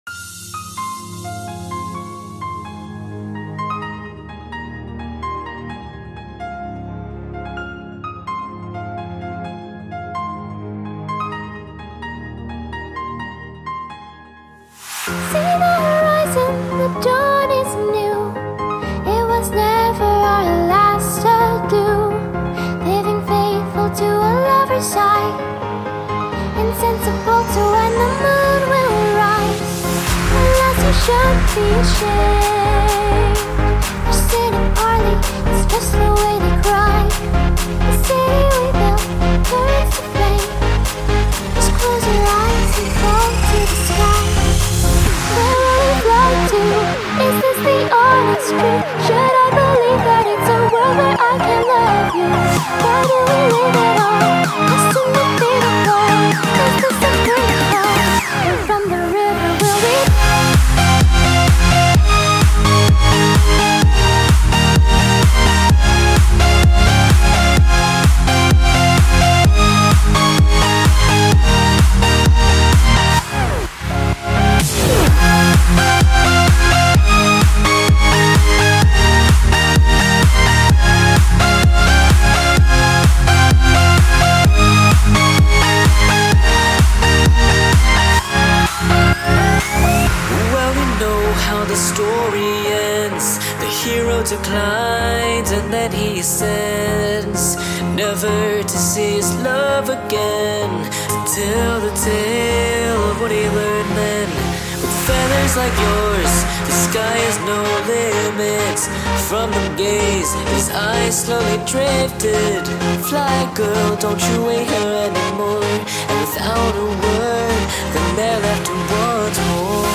Melodic House/Electro House